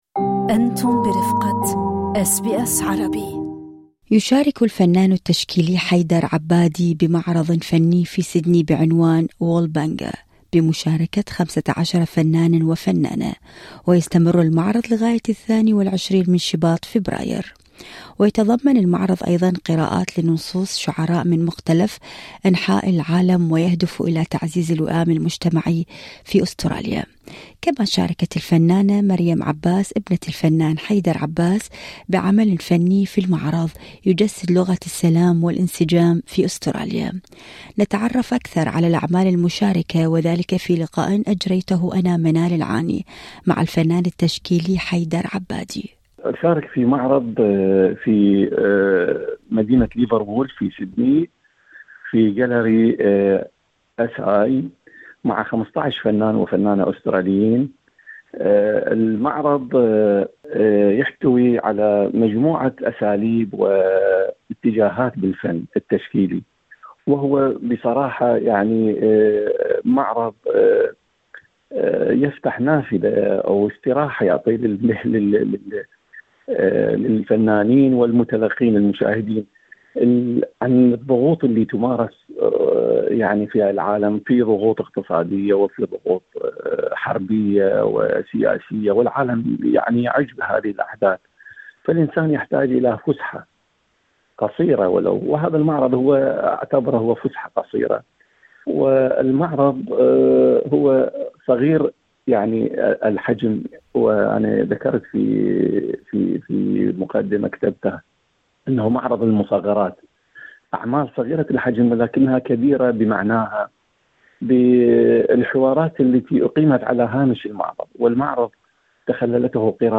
نتعرف أكثر على الأعمال المشاركة في اللقاء الاذاعي اعلاه